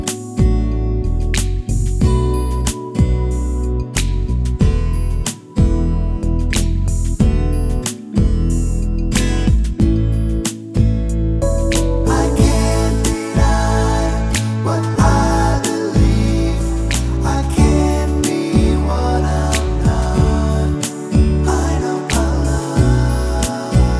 for duet Pop (2010s) 4:34 Buy £1.50